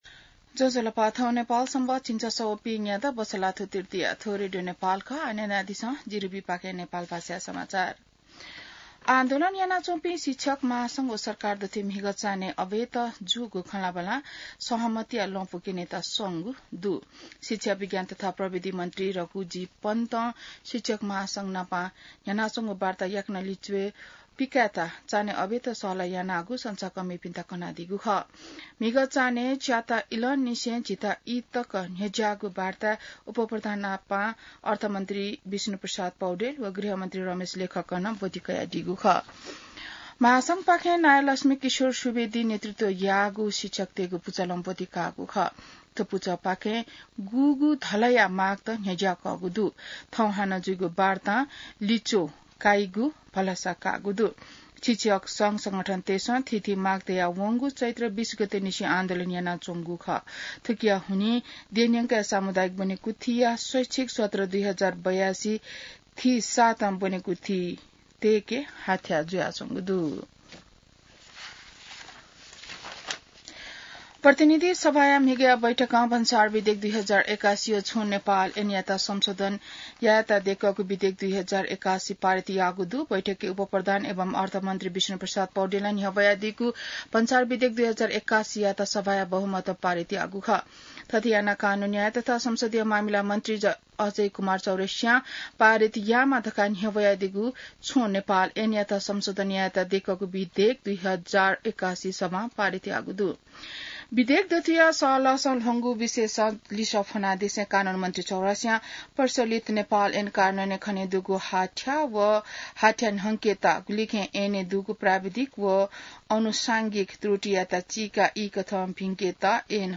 नेपाल भाषामा समाचार : १७ वैशाख , २०८२